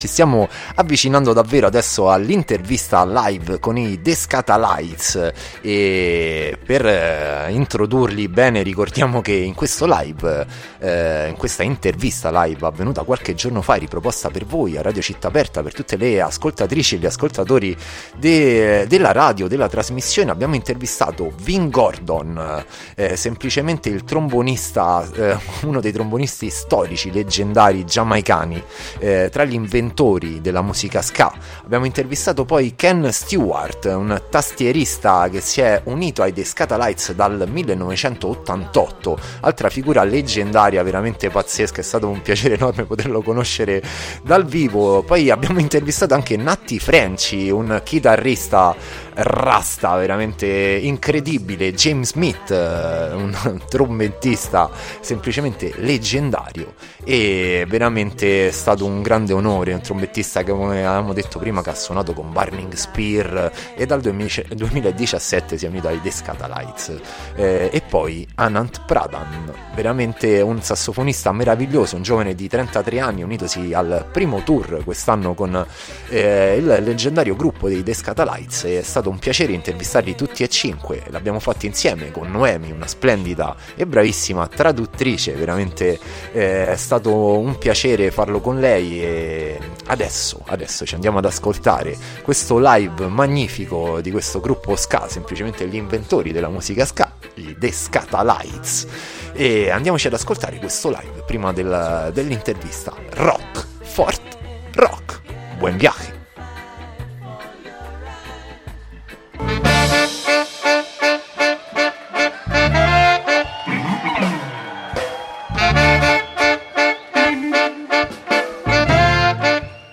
ARTISTA A LA VISTA | INTERVISTA LIVE | THE SKATALITES | Radio Città Aperta
Artista a la Vista è andato a intervistare chi ha fatto la storia della musica. I The Skatalites, gruppo nato in Jamaica nel 1964 con base negli Stati Uniti, incontrati a Fuerteventura, Isole Canarie.
Artista-a-la-Vista-Intervista-The-Skatalites.mp3